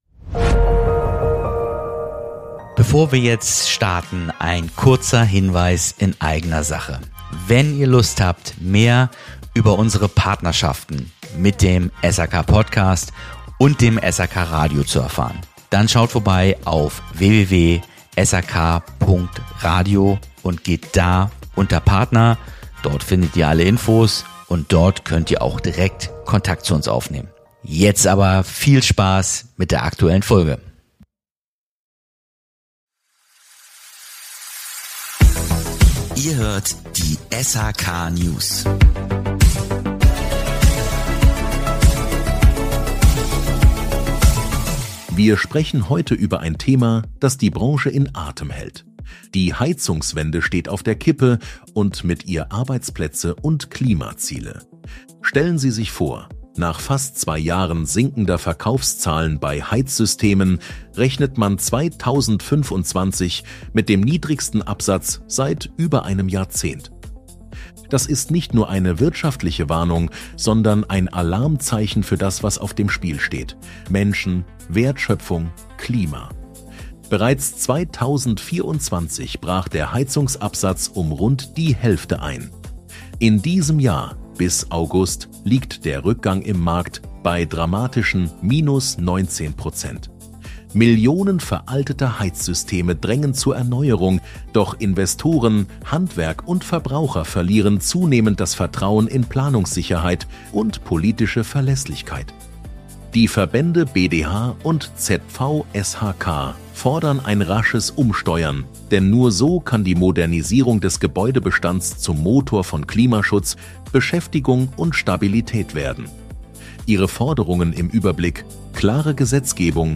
Heizungswende in Gefahr: Im Gespräch mit BDH und ZVSHK geht’s um